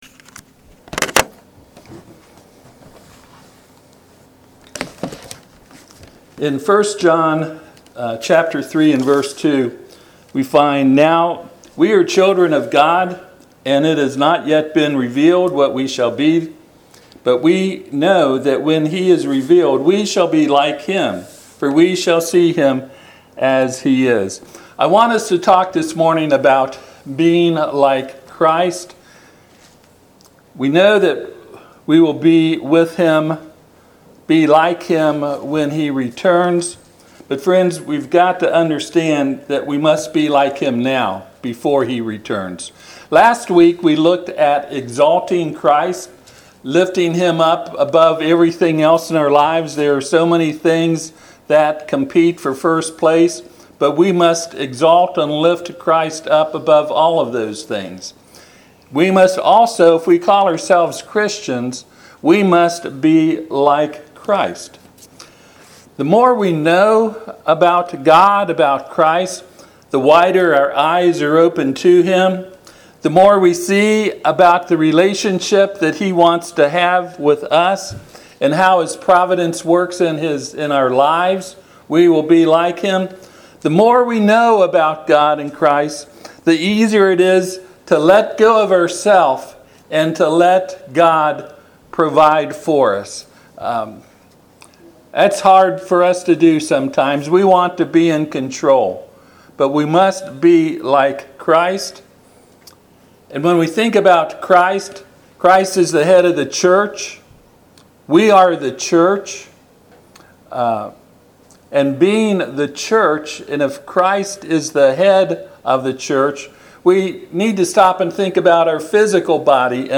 1 John 3:2 Service Type: Sunday AM Topics